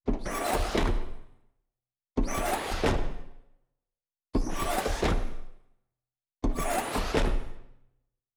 SFX_RoboSteps_Squeaky_04.wav